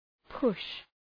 Προφορά
{pʋʃ}